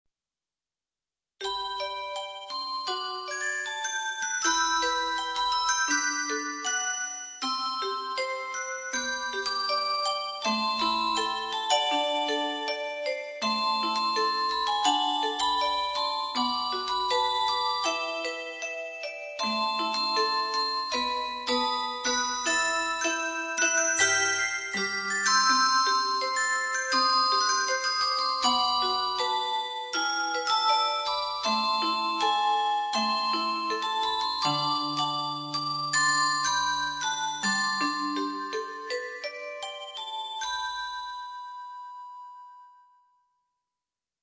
Bell Mix (Format:mp3,56kBit/s,24kHz,Stereo Size:303kB)